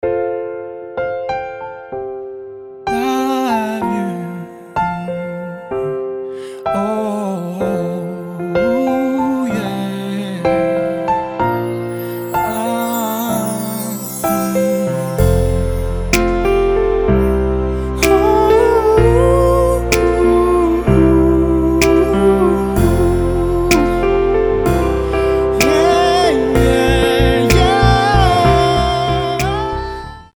• Качество: 320, Stereo
поп
красивые
без слов
пианино
романтичные
фортепиано
Мелодичная музыка